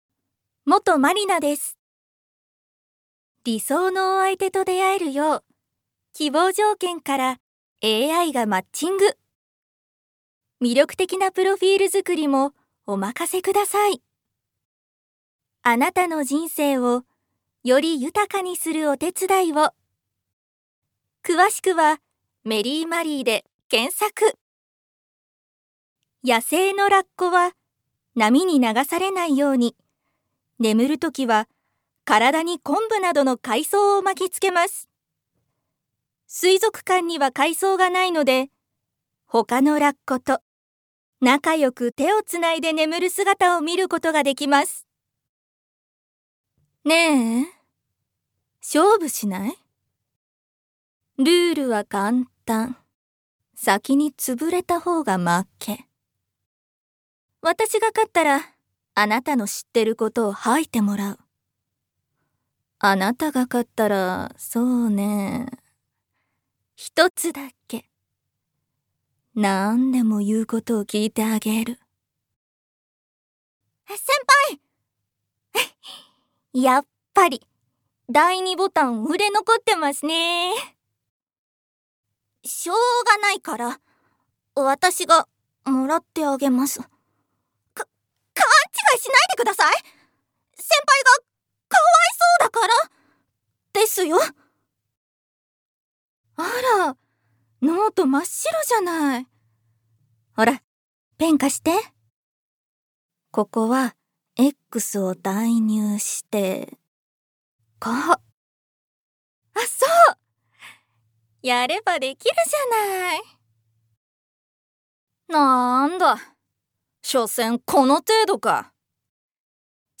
声質：中高音
サンプルボイスの視聴